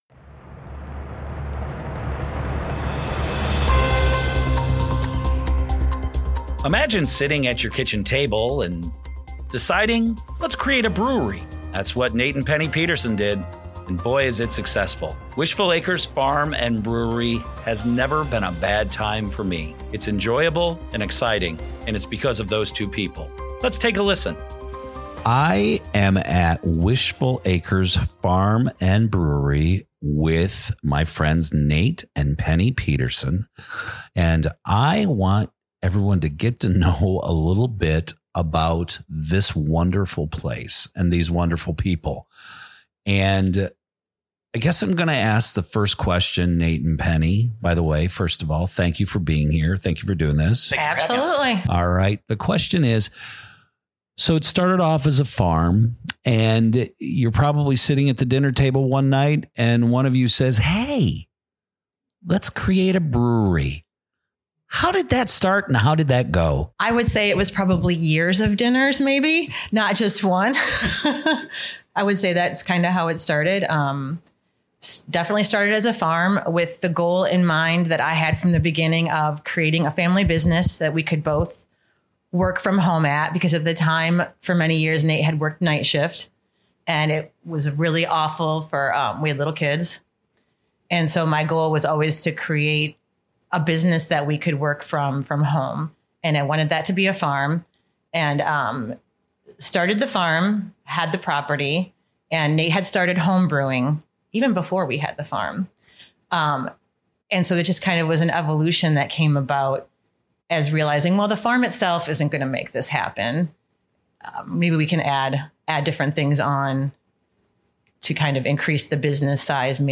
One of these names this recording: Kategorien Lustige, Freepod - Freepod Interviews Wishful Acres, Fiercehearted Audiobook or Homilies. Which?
Freepod - Freepod Interviews Wishful Acres